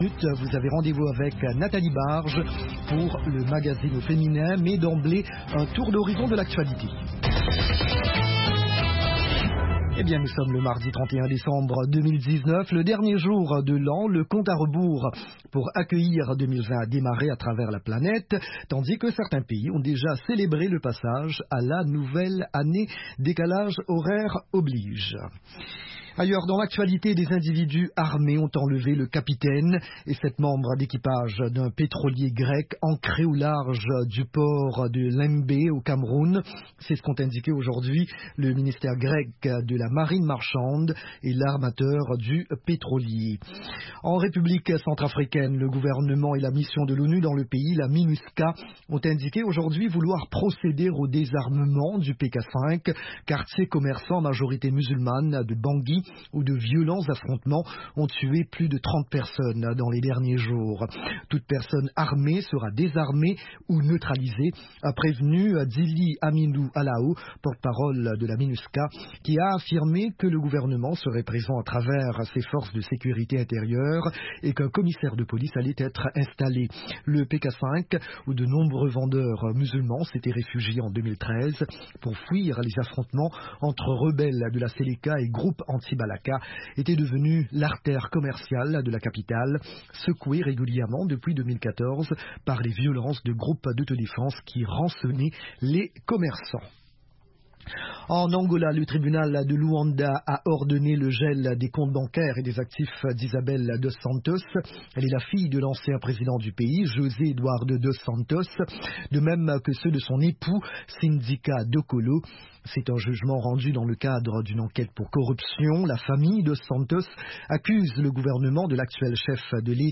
3 min Newscast